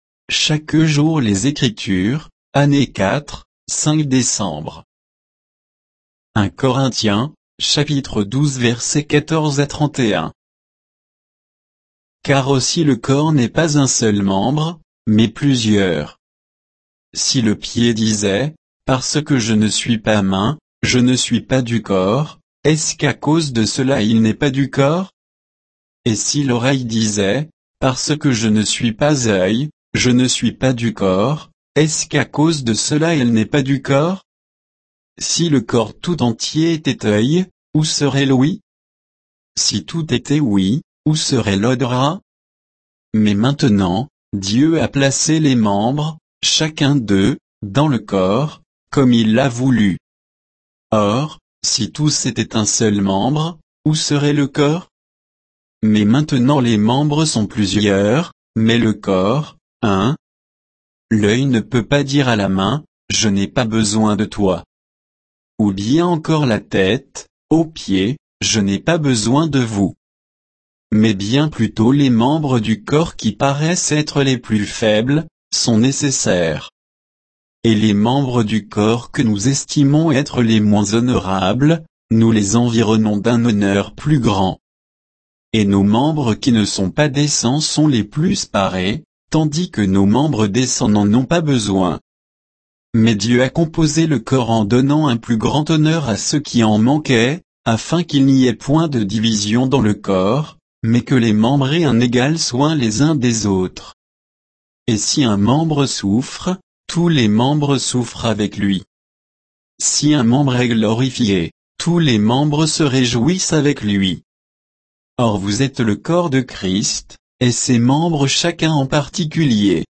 Méditation quoditienne de Chaque jour les Écritures sur 1 Corinthiens 12, 14 à 31